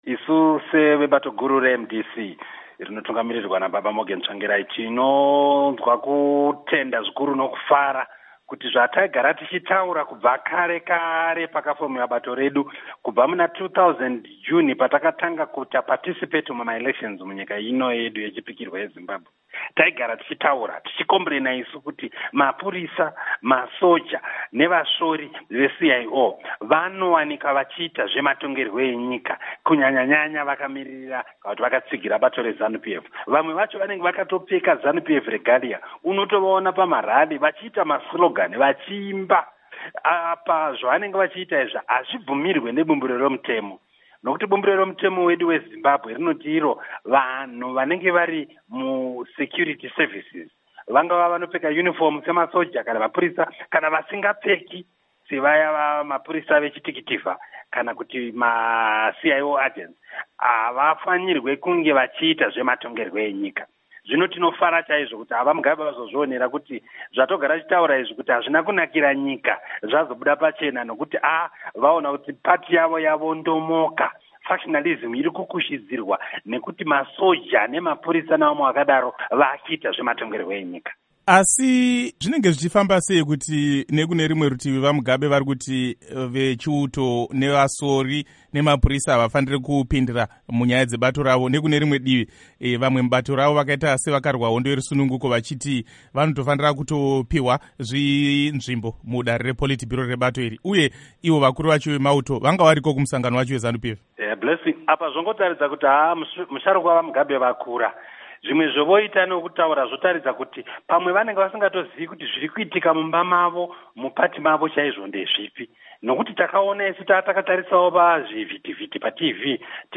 Hurukuro naVaObert Gutu